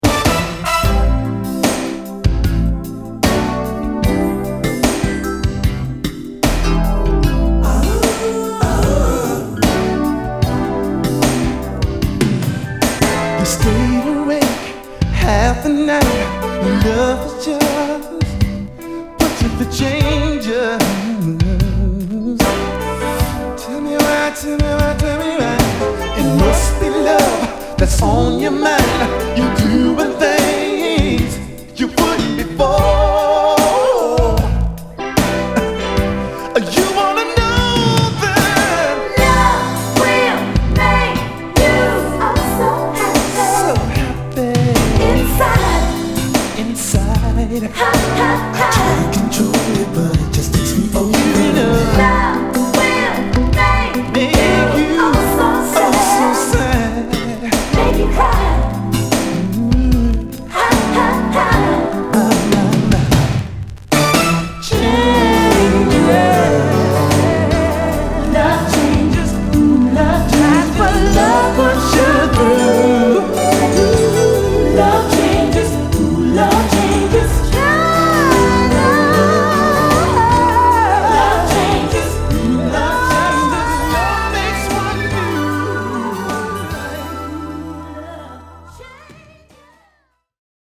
・ DISCO 80's 12'